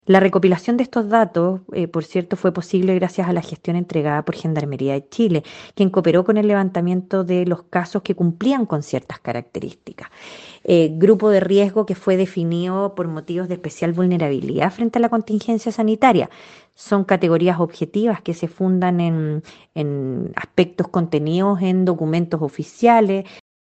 En conversación con Radio Bío Bío, la defensora regional Metropolitana Norte, Alejandra Lobos, afirmó que la recopilación de los datos de los internos fueron proporcionados por Gendarmería.